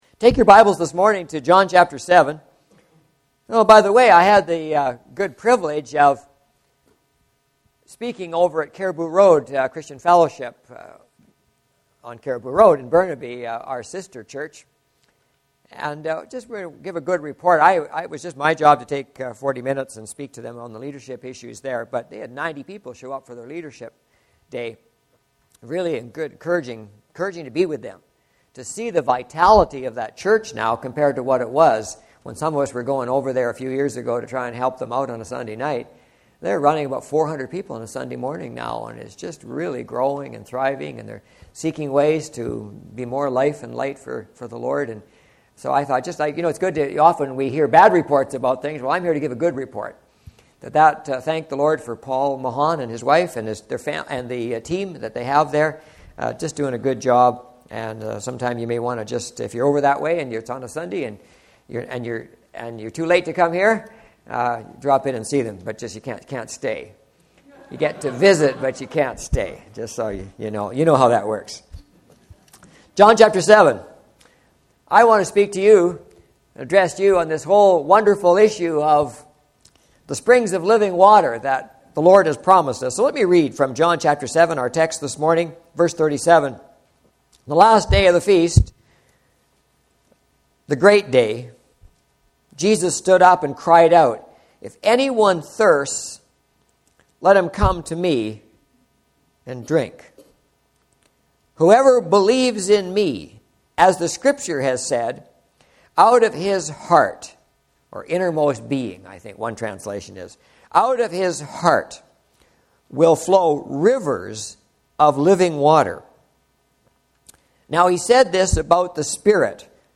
Valley Church - Lynn Valley - North Vancouver - Sermons